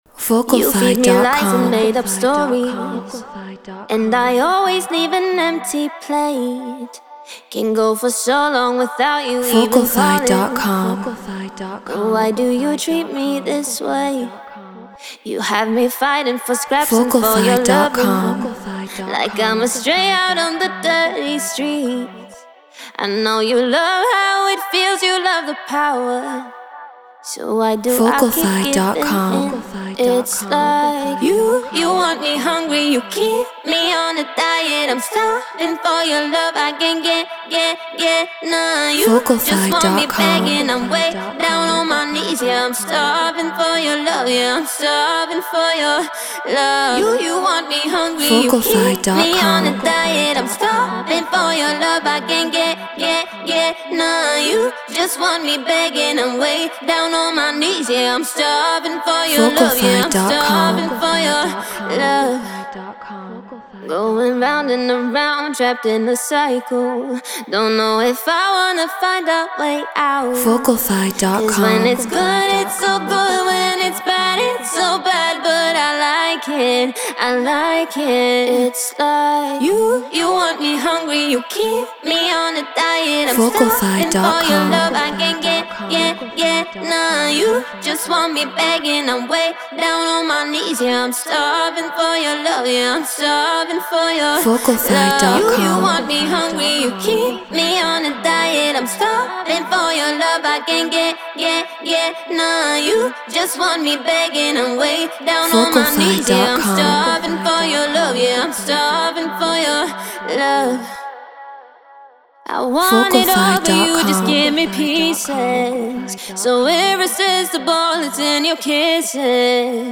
House 124 BPM Bmin
Shure SM7B
Treated Room